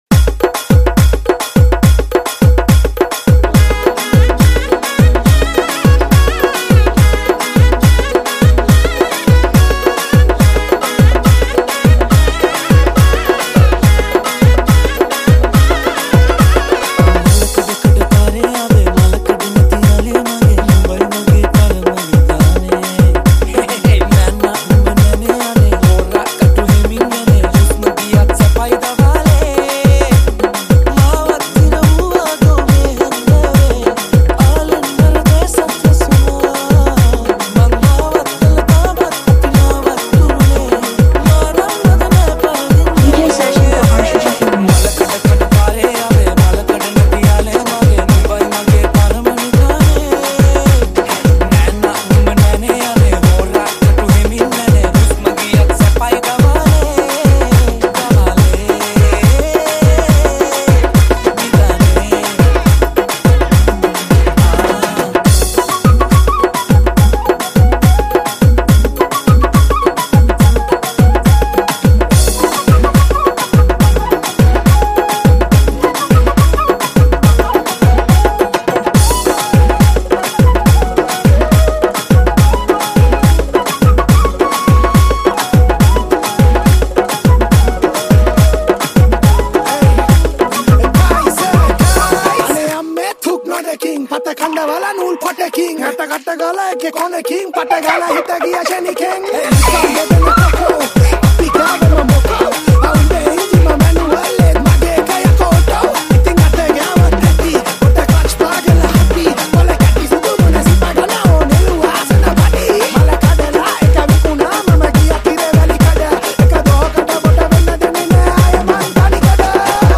High quality Sri Lankan remix MP3 (2.6).